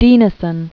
(dēnĭ-sən, dĭnĭ-), Isak Pen name of Baroness Karen Blixen, born Karen Christentze Dinesen. 1885-1962.